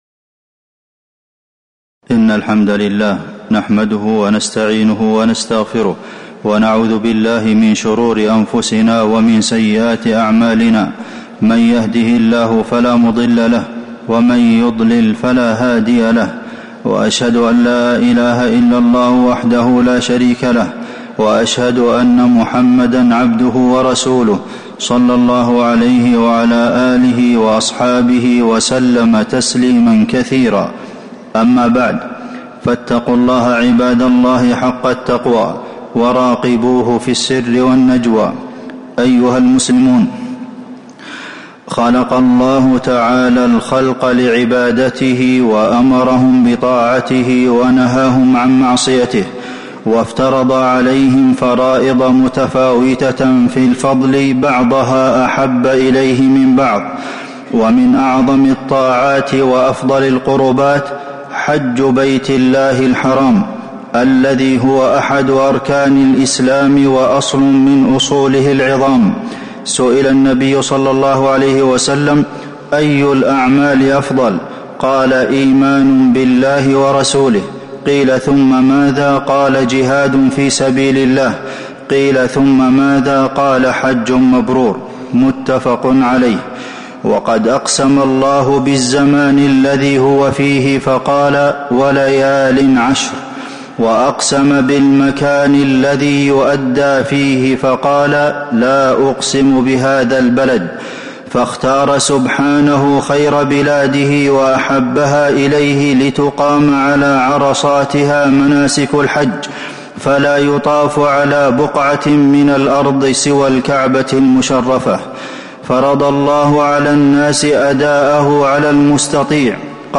تاريخ النشر ١٦ ذو القعدة ١٤٤٥ هـ المكان: المسجد النبوي الشيخ: فضيلة الشيخ د. عبدالمحسن بن محمد القاسم فضيلة الشيخ د. عبدالمحسن بن محمد القاسم من مقاصد الحج